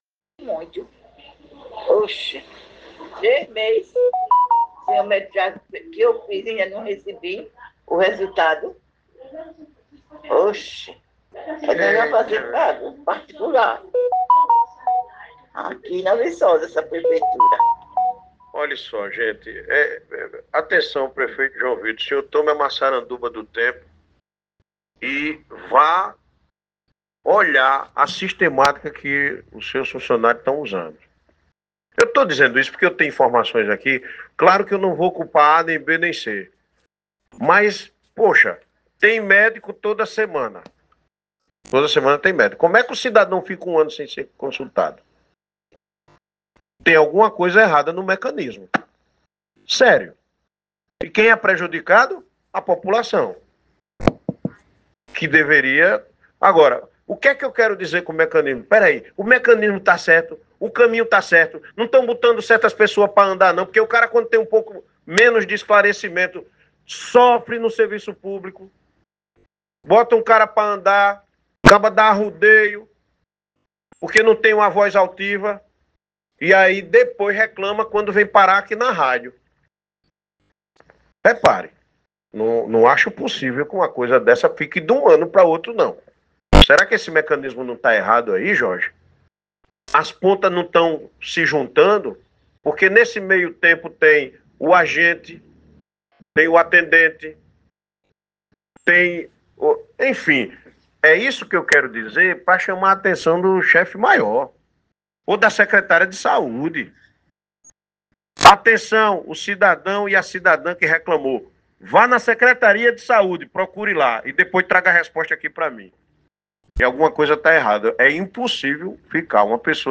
Durante participação ao vivo na Rádio Princesa FM